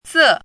chinese-voice - 汉字语音库
ze4.mp3